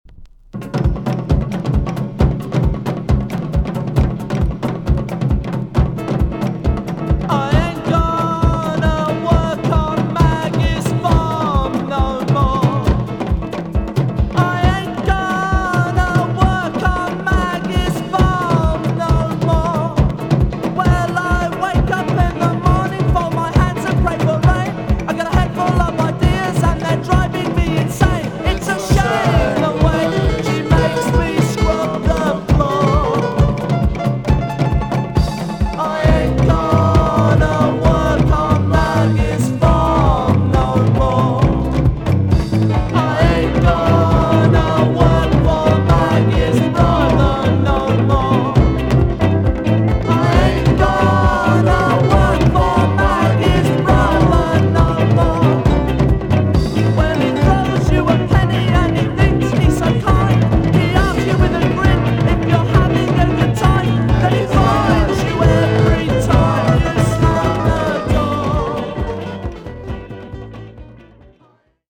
EX- 音はキレイです。